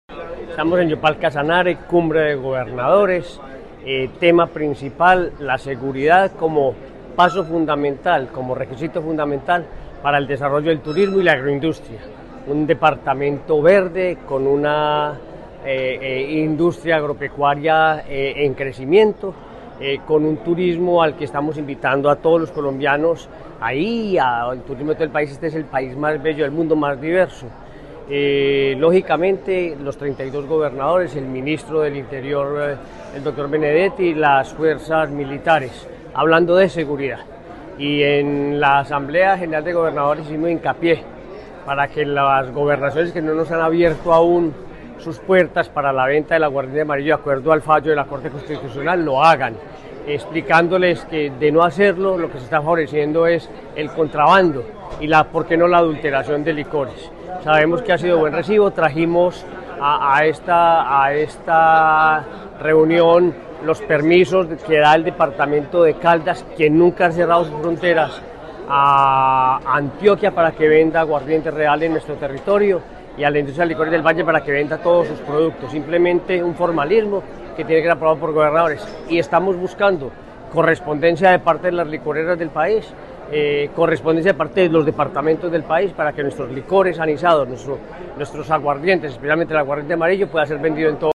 Durante su intervención, el mandatario caldense destacó el potencial turístico de Caldas, resaltando la riqueza natural, cultural y cafetera del territorio como una oportunidad de desarrollo económico y social.
Henry Gutiérrez Ángel, gobernador de Caldas